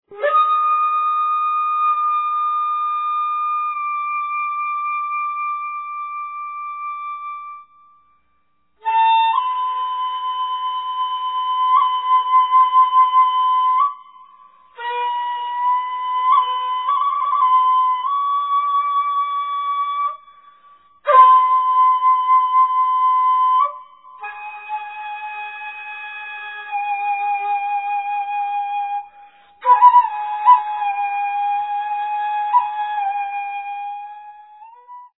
serene solo pieces